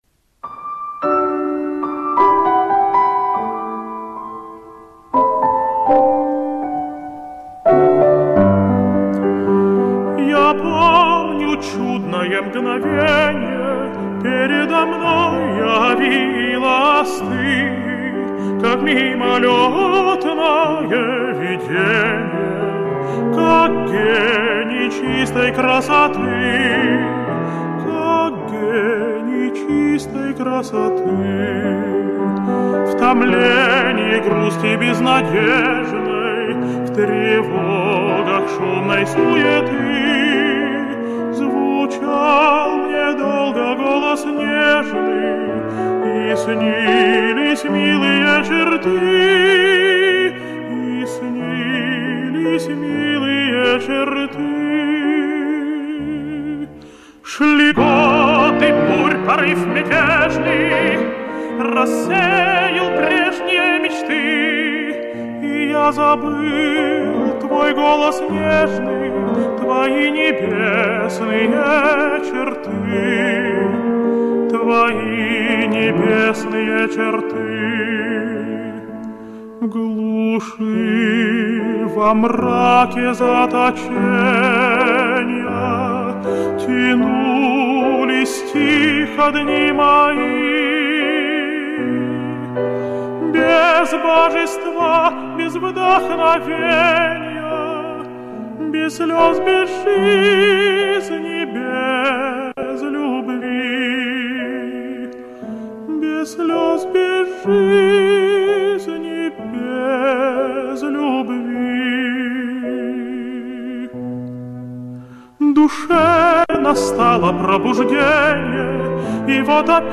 Я думаю, лучшим моим поздравлением наших женщин с их праздни ком будет "Я помню чудное мгновенье" в исполнении Даниила Штоды.